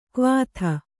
♪ kvātha